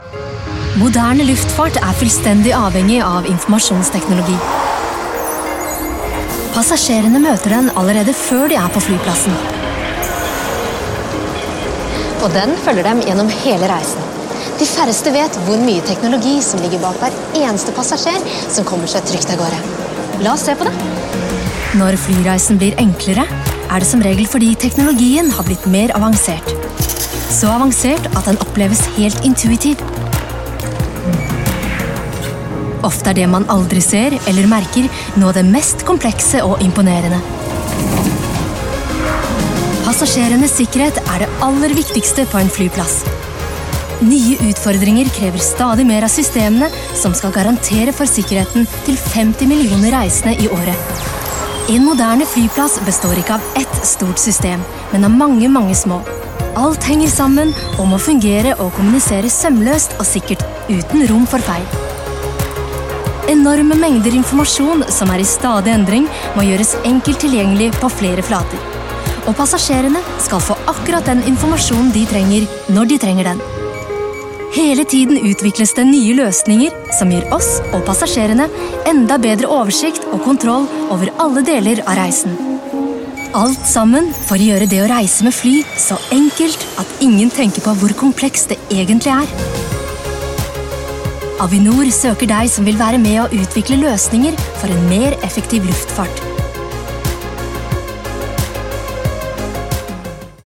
Female
Friendly, Confident, Character, Corporate, Energetic, Natural, Warm, Engaging
Microphone: SM Pro Audio MC01